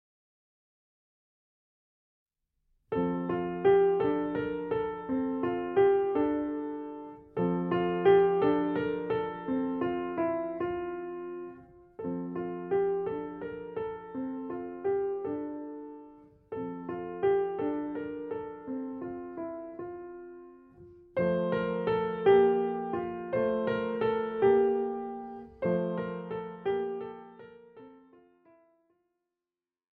41 leichte Klavierstücke
Besetzung: Klavier